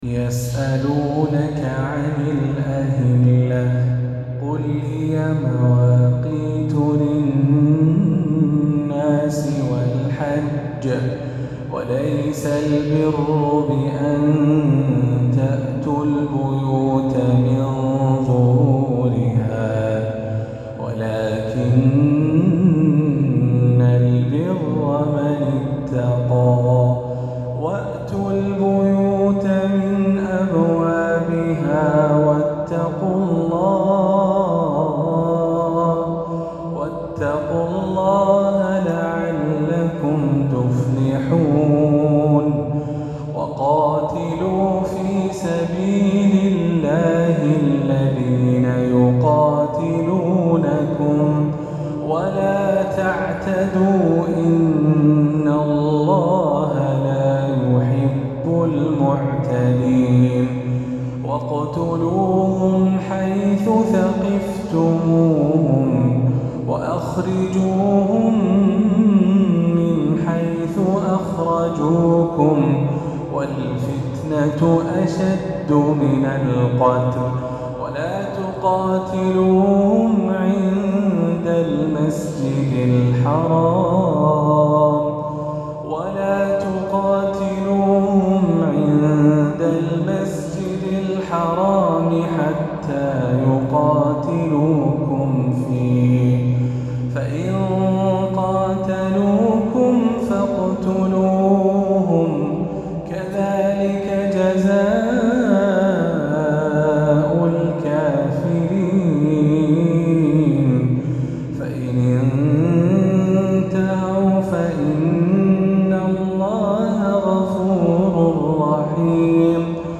عشائية الإثنين